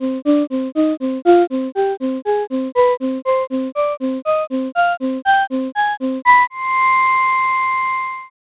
This tutorial will demonstrate how waveforms of different types can be added together at the instrument level.
* A basic layered synthesis instrument
There are four different sounds, and rather than linking them sequentially they are run in parallel.
BreathyFluteTest.au